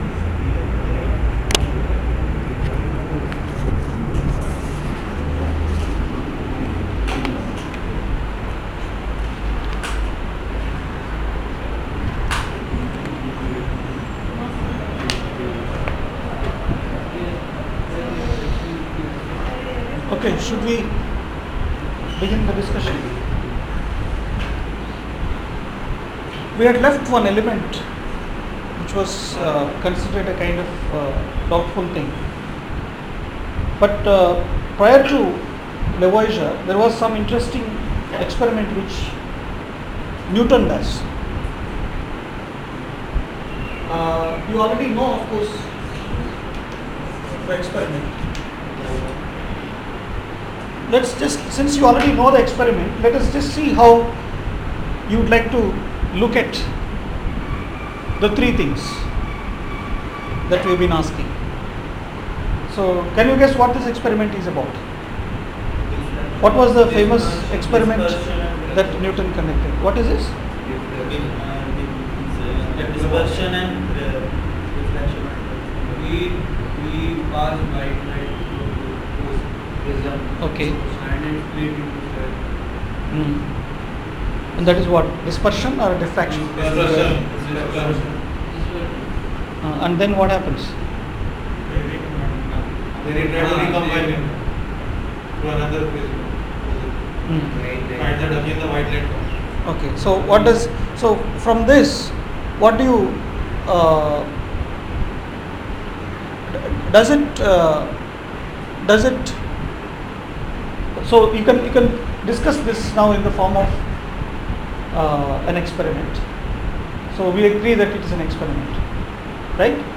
lecture 17 — Invitation to History of Science: H201